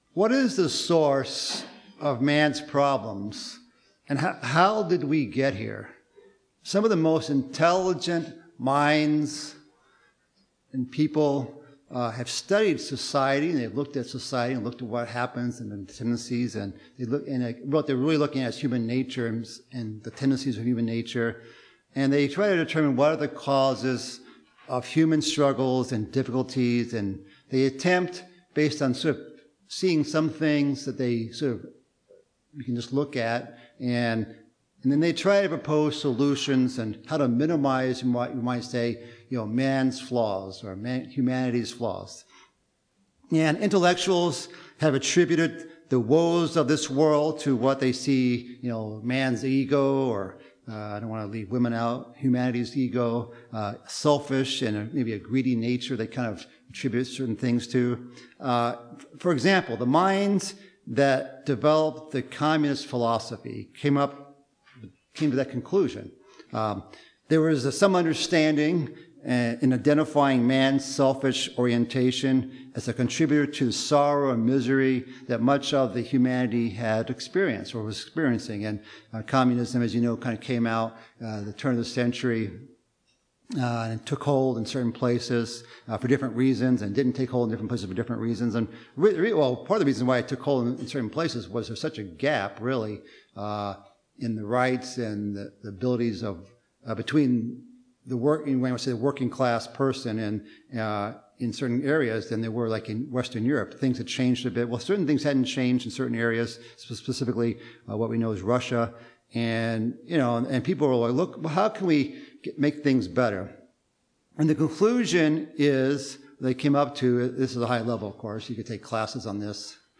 Atonement is the 5th Annual Holy Day and a milestone in God's plan to dwell with Holy Children. This sermon answers four main questions: a. Why is the world the way it is, and what does that have to do with Atonement? b. Who is the King of over all the Children of Pride c. Why do we Afflicting our Souls on the Day of Atonement d. How does all this relate to a Goat taken to the Wilderness?